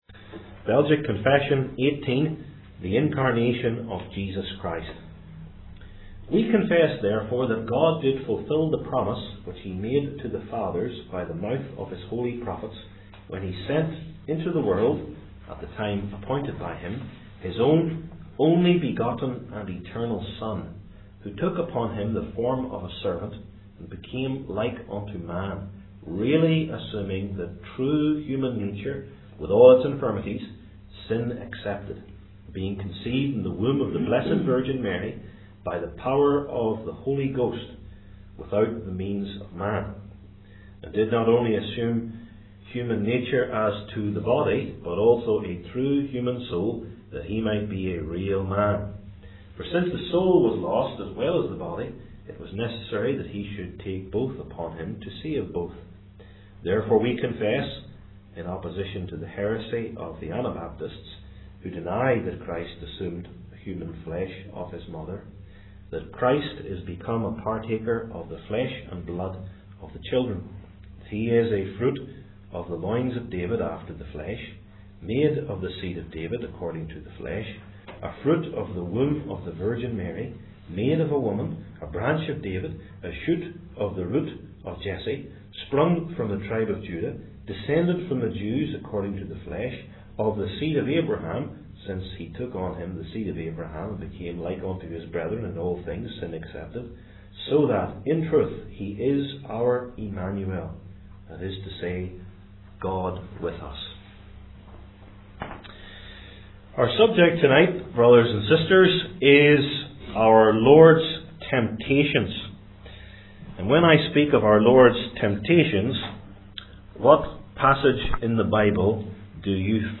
Belgic Confession Classes